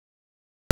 Pronúnciase como (IPA) /ˈkwɔ.tɐ/